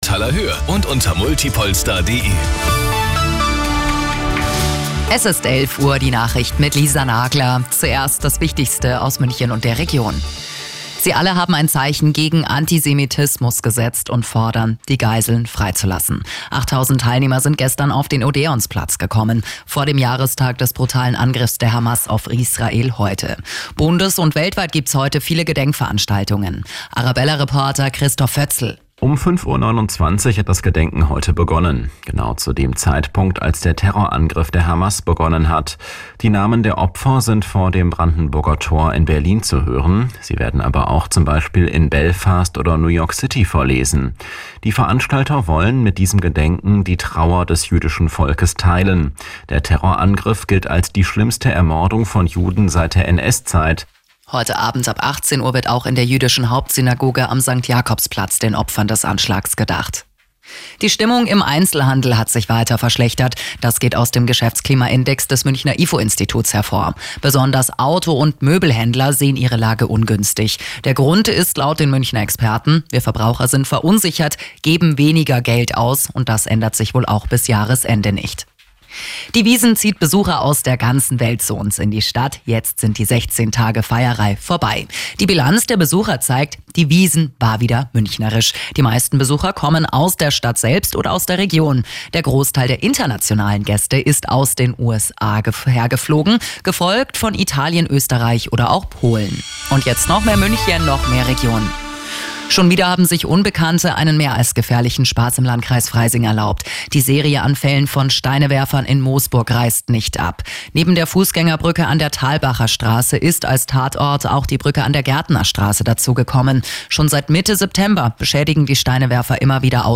Die Radio Arabella Nachrichten von 14 Uhr - 07.10.2024